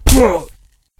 hit3.ogg